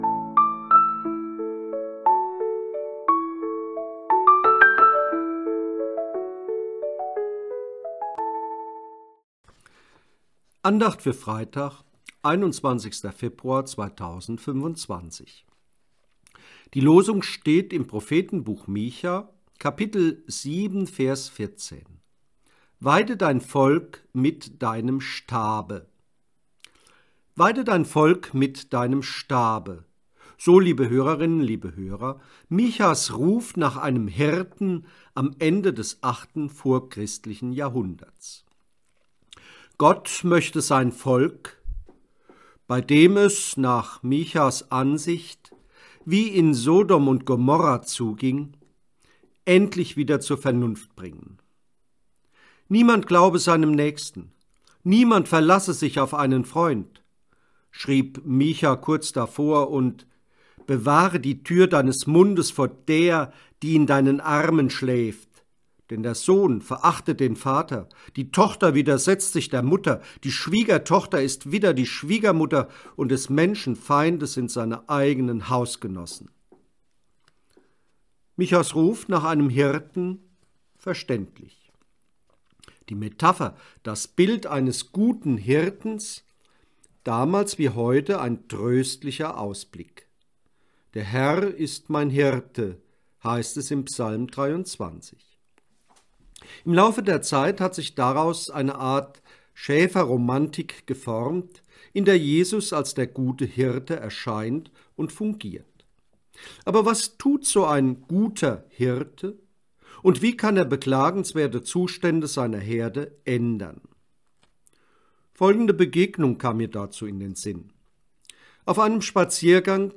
Losungsandacht für Freitag, 21.02.2025